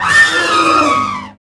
AN  ELEPHA00.wav